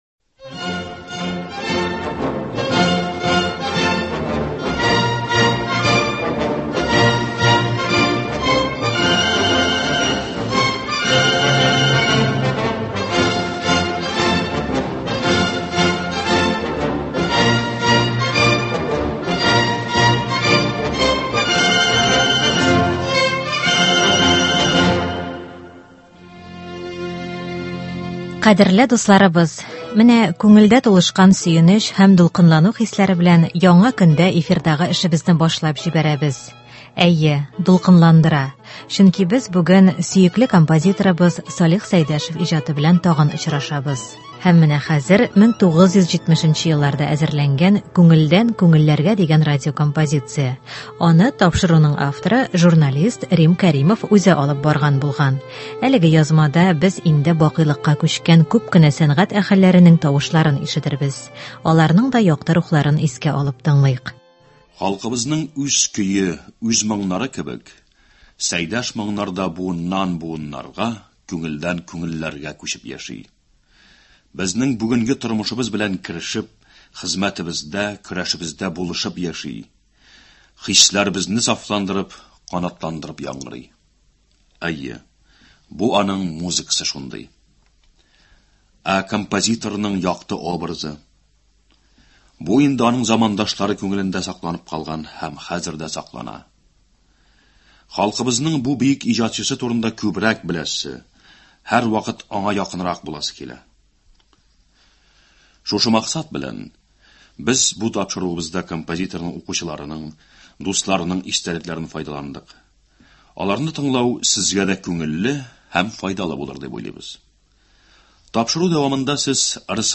«Күңелдән – күңелләргә». Салих Сәйдәшев иҗатына багышланган әдәби-музыкаль композиция.
Хәзер 1970 елларда әзерләнгән “Күңелдән – күңелләргә” дигән радиокомпозиция игътибарыгызга тәкъдим ителә.
Әлеге язмада без инде бакыйлыкка күчкән күп кенә сәнгать әһелләренең тавышларын ишетербез.